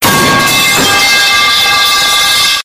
metal.wav